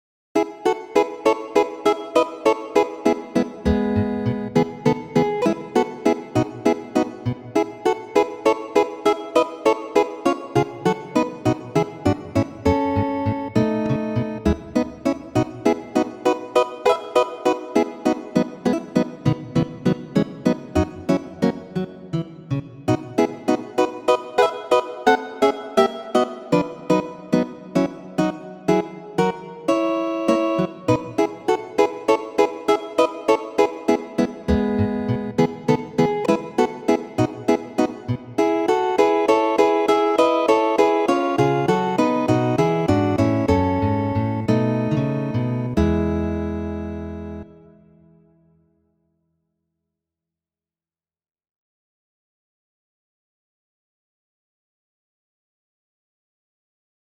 Preludo n-ro 4-a, de Francisco Tárrega, midigita de mi mem.